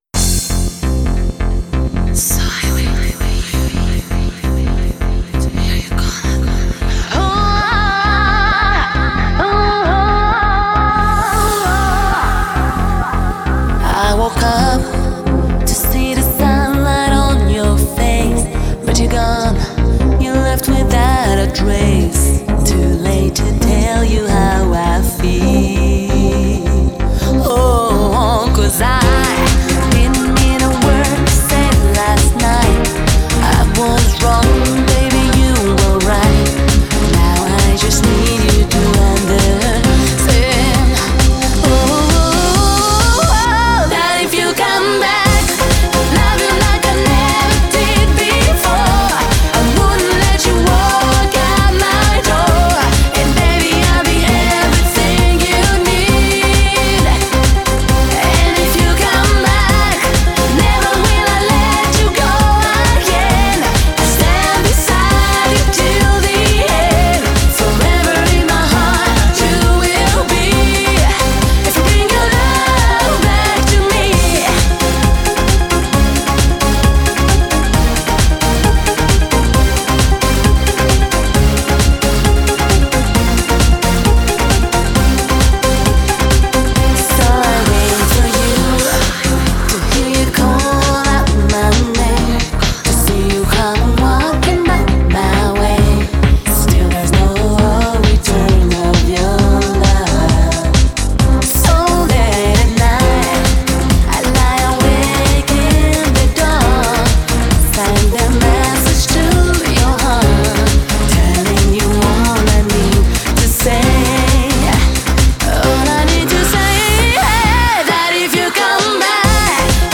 DJ舞曲，都是快节奏、劲爆的音乐。